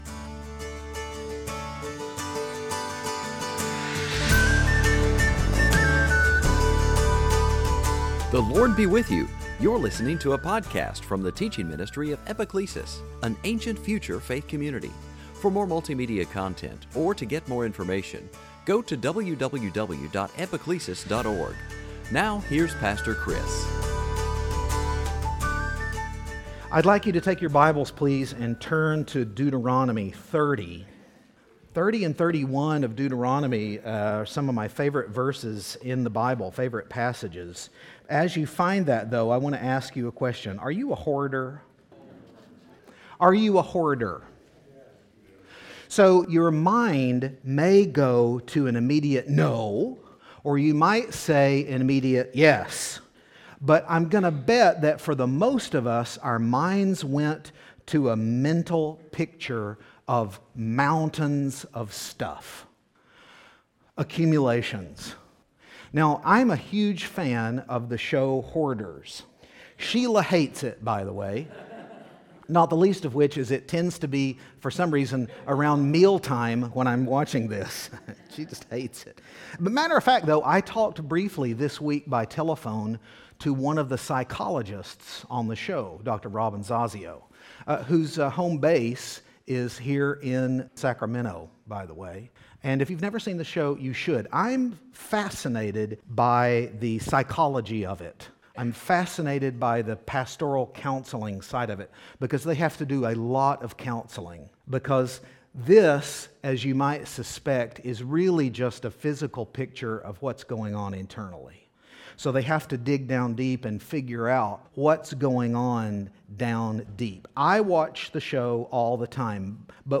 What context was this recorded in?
Service Type: Epiphany